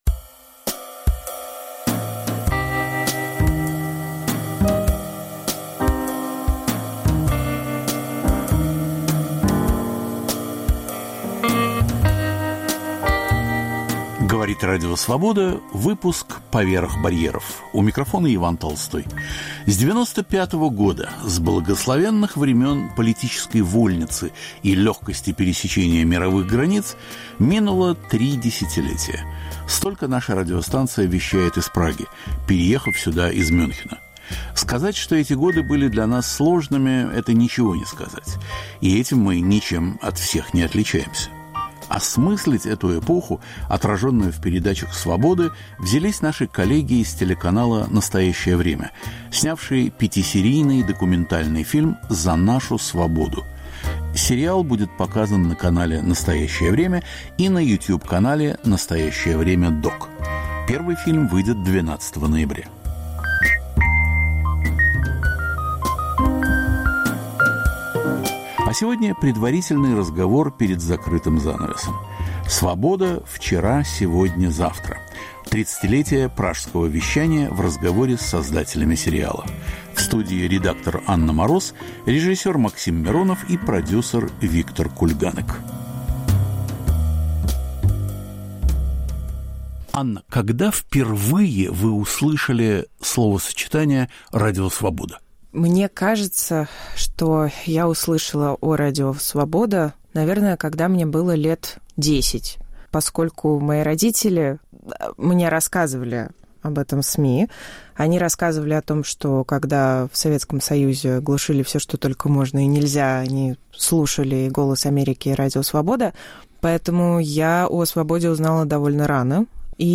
Круглый стол, посвященный этому сериалу, рассказывает об этапах работы, о задачах и взглядах создателей на радио, которое сегодня снова "в изгнании".